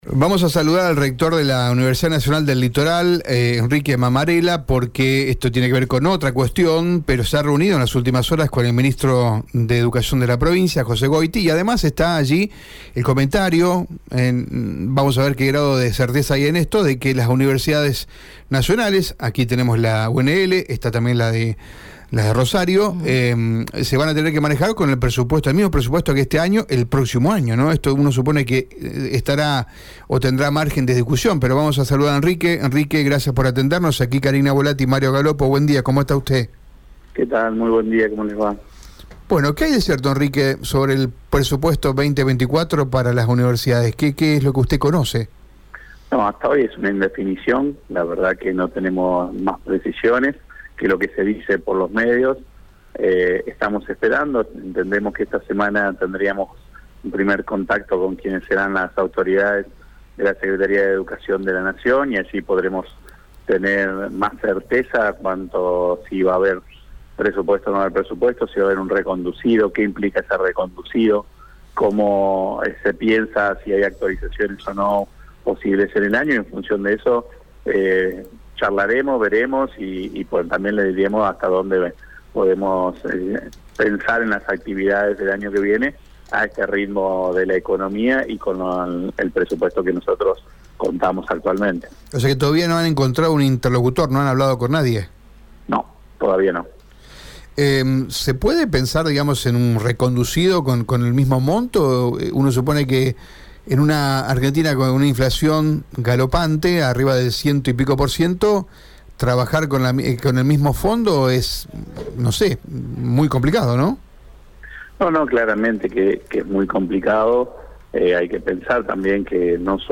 Escucha la palabra de Enrique Mammarella en Radio EME: